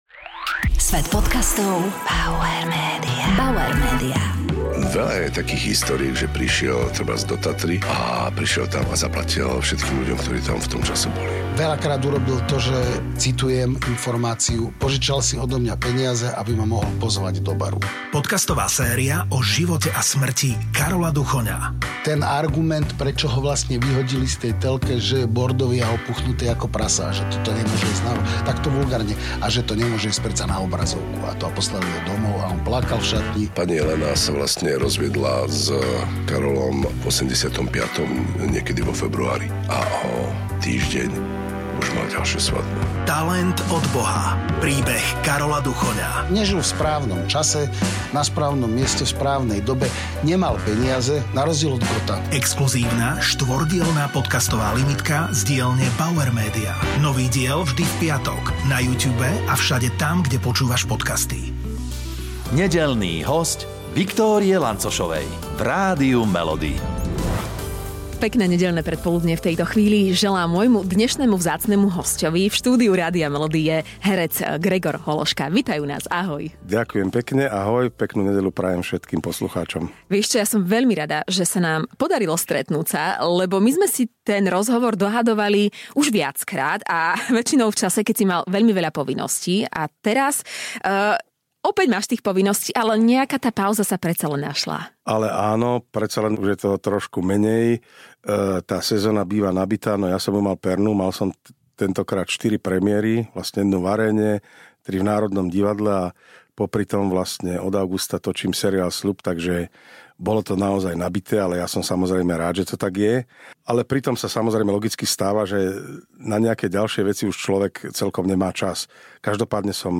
Hosťom dnešnej časti podcastu je výborný slovenský herec Gregor Hološka.